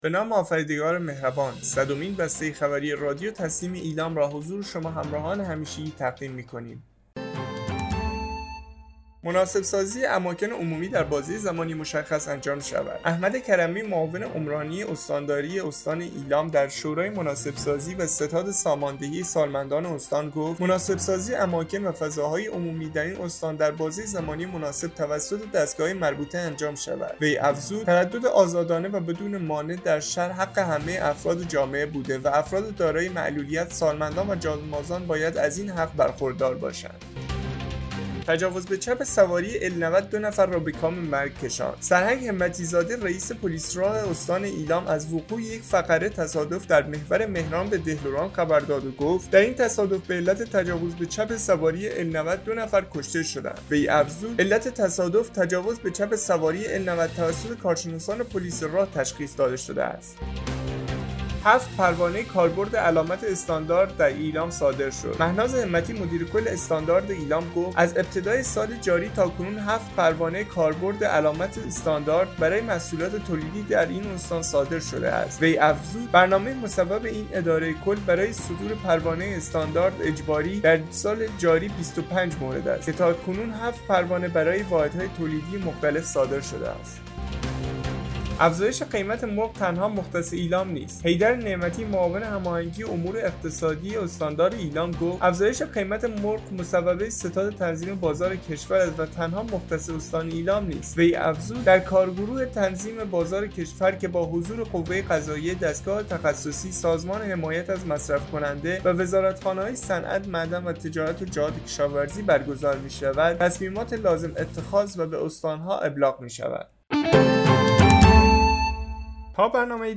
به گزارش خبرگزاری تسنیم از ایلام, صدمین بسته خبری رادیو تسنیم استان ایلام با خبرهایی چون؛ مناسب سازی اماکن عمومی در بازه زمانی مشخص انجام شود، تجاوز به چپ سواری ال 90 دو نفر را به کام مرگ فرستاد، هفت پروانه کاربرد علامت استاندارد در ایلام صادر شد و افزایش قیمت مرغ تنها مختص ایلام نیست منتشر شد.